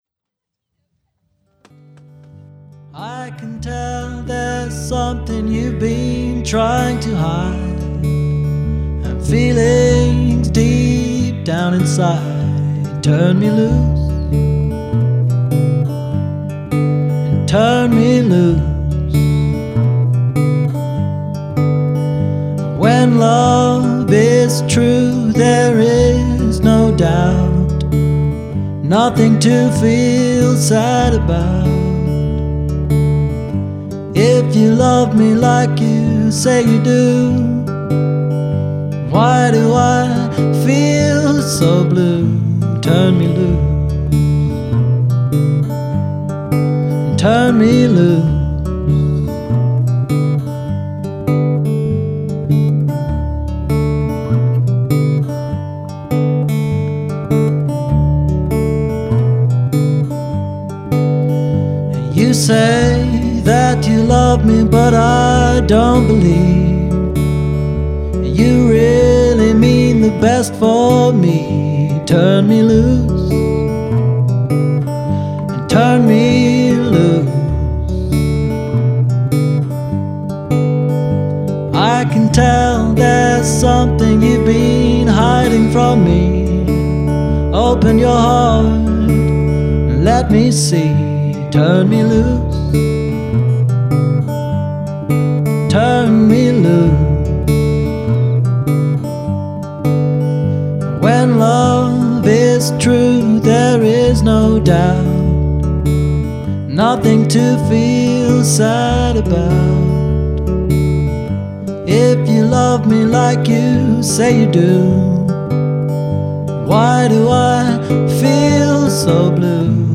Recorded live at Rollright Fayre